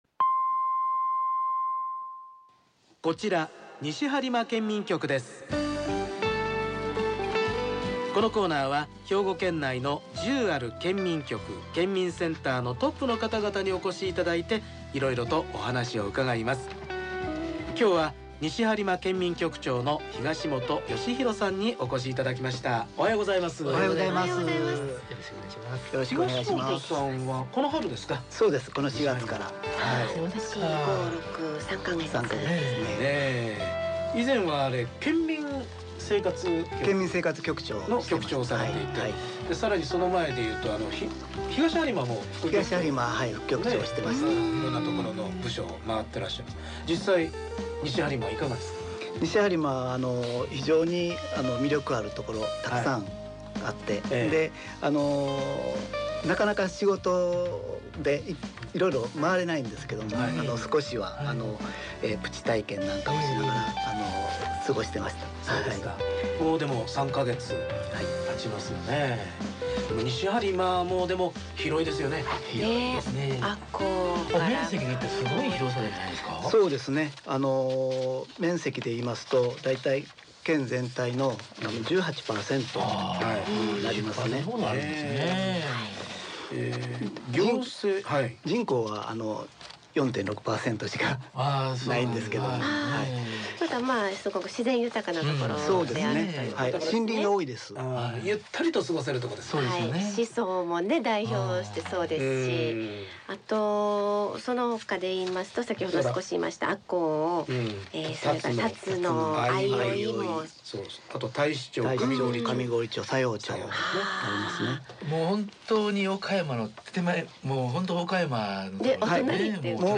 2017-07-06 『こちら西播磨県民局です！』／西播磨県民局長 東元良宏さん
このコーナーは･･ 兵庫県内の県民局の方をお招きして･･ 色々とお話をうかがいます。